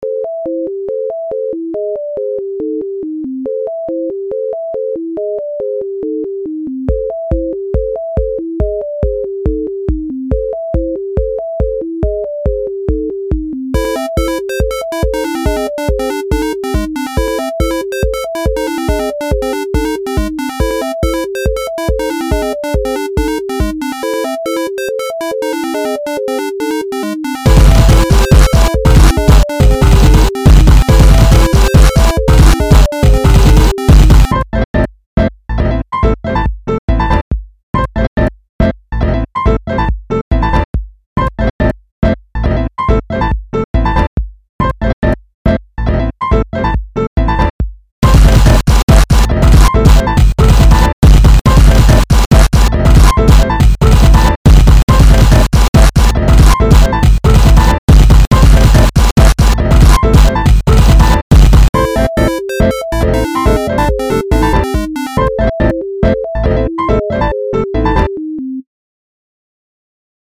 SLICED AND DICED BEATS WITH PIANO GROOOOVE.